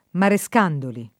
[ mare S k # ndoli ]